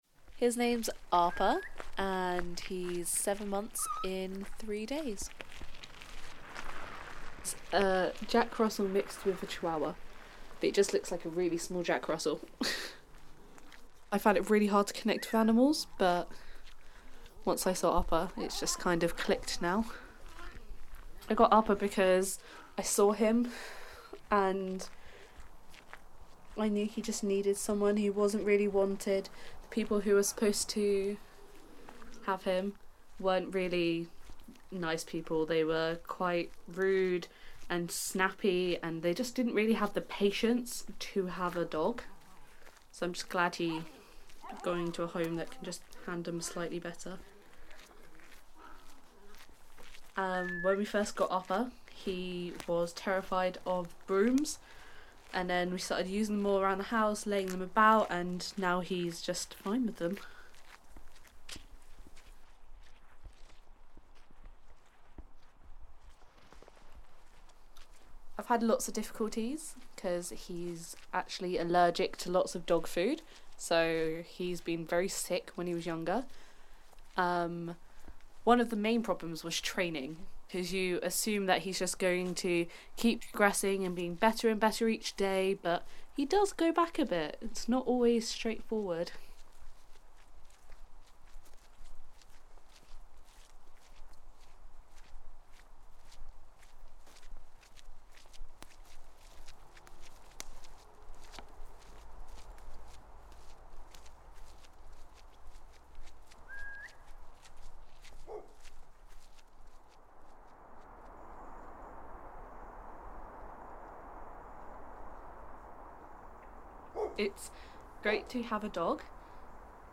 A brief look into owning dogs from the perspective of a first time dog owner. Recommended for listening on Headphones.